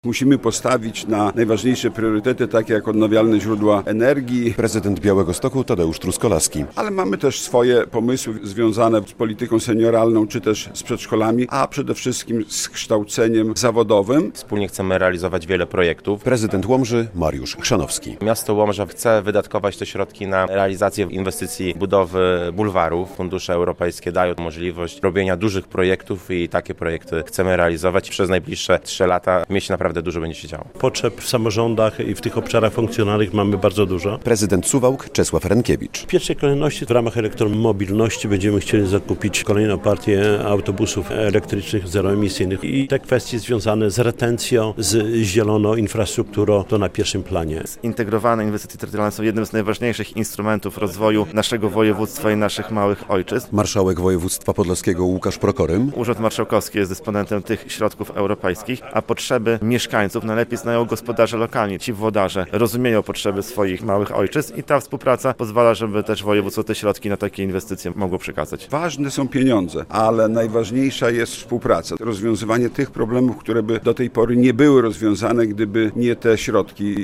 Pieniądze dla obszarów funkcjonalnych - relacja